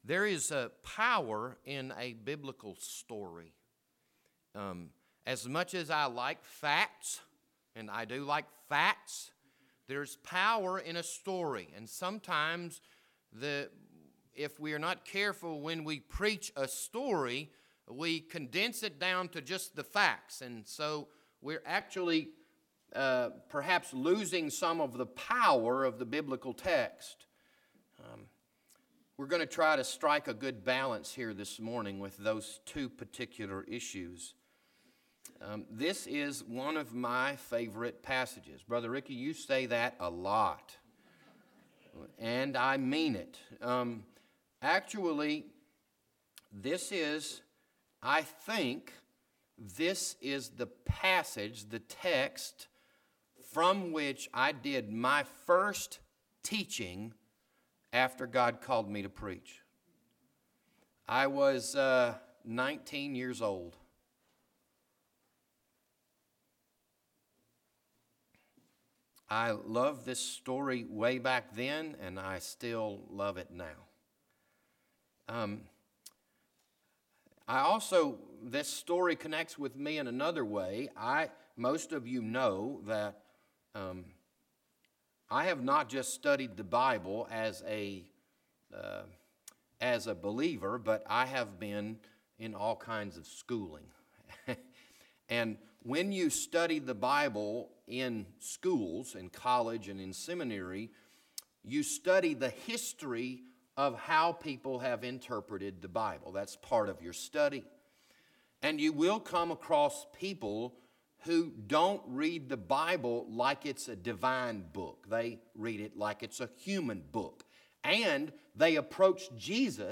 This Sunday morning sermon was recorded on April 14, 2019.